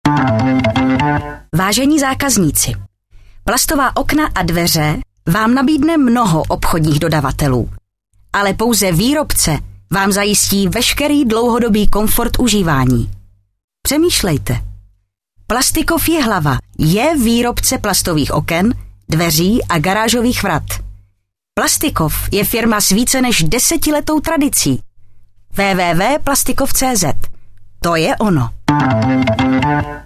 Slogan na radiu Vysočina č.1